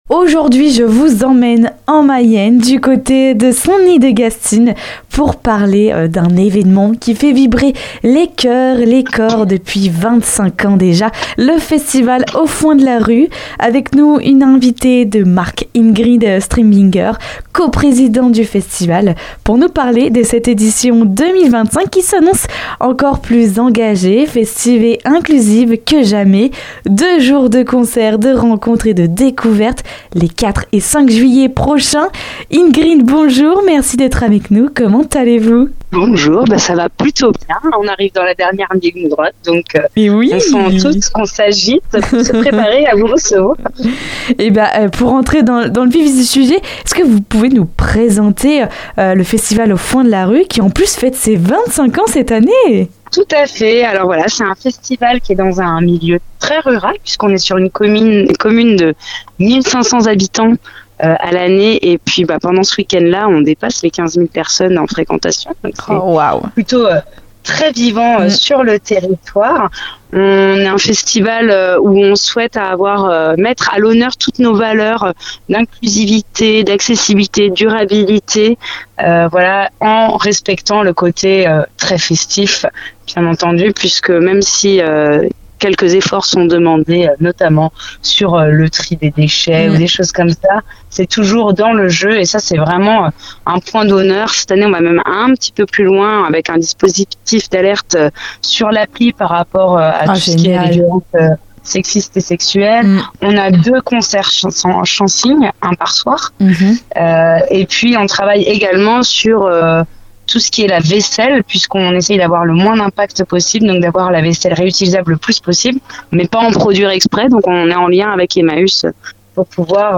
Elle est avec nous pour discuter de la programmation du festival, mais aussi pour nous partager les valeurs de bel événement. Pour en savoir plus sur cet rencontre n'hésitez pas à écouter jusqu'au bout l'interview, et pourquoi pas vous aussi, faire partie des festivaliers !